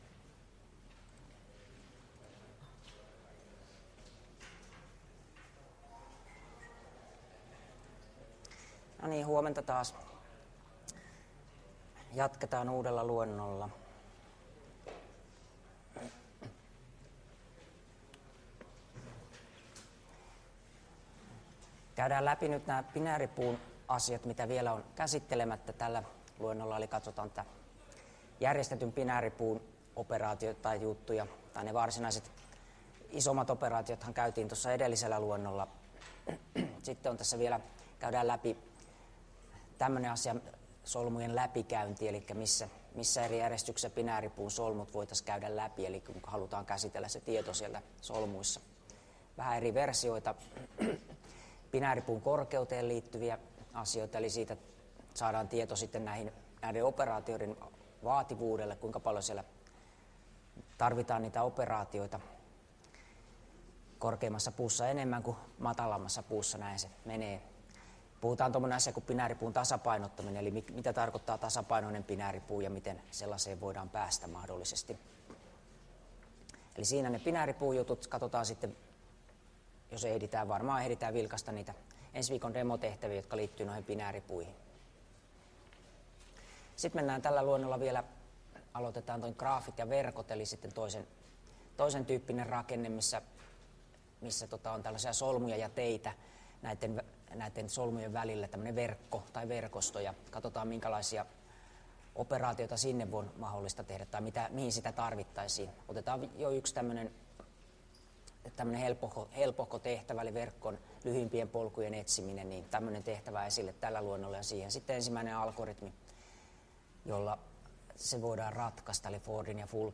Luento 8 — Moniviestin